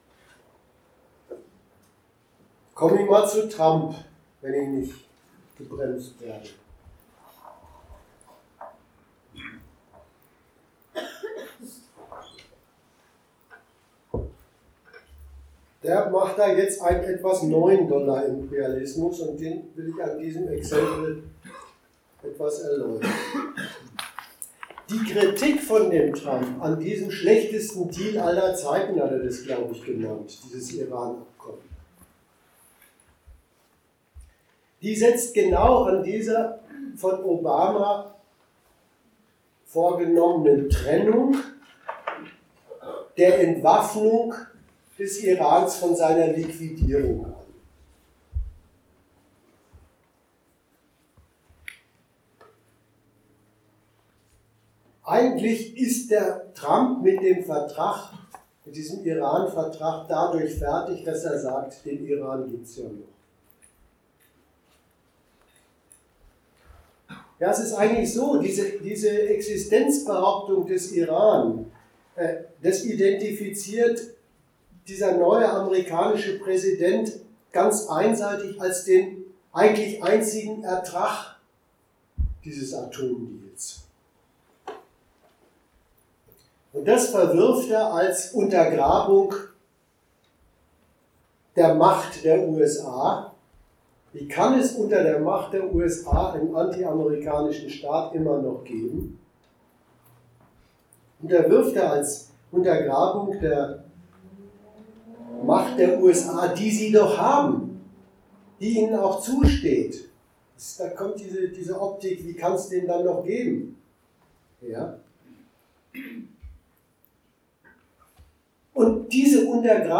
Datum 25.10.2018 Ort Bremen Themenbereich Staatenkonkurrenz und Imperialismus Veranstalter Argudiss Dozent Gastreferenten der Zeitschrift GegenStandpunkt Die US-Führung kündigt den Atomdeal mit Iran.